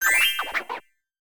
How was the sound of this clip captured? This file is an audio rip from a(n) Nintendo DS game.